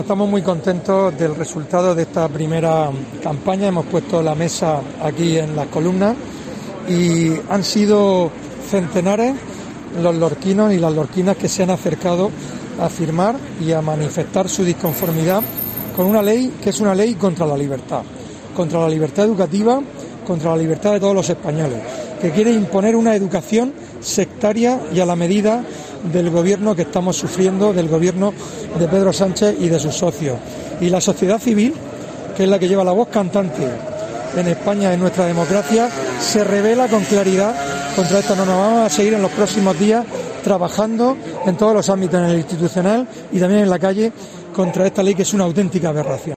Fulgencio Gil, potavoz PP sobre Ley Celaá